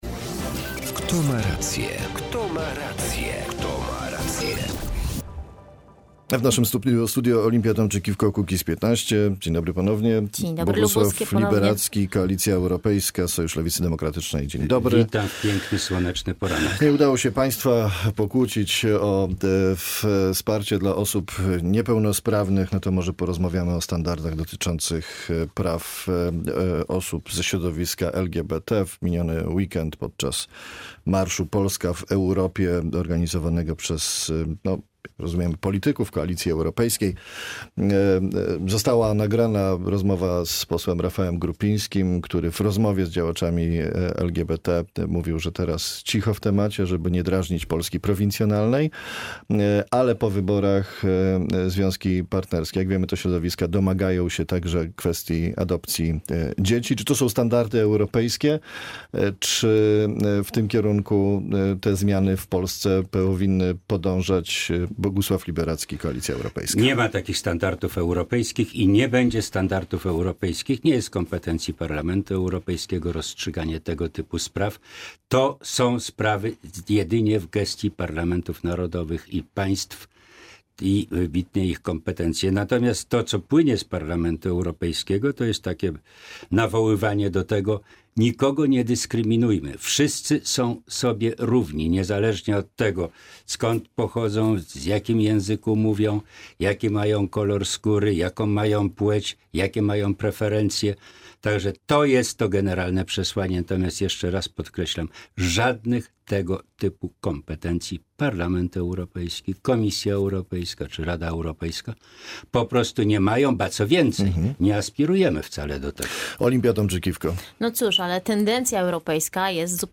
Spotkanie gości reprezentujących różne stanowiska, którzy konfrontują je w rozmowie jeden na jednego.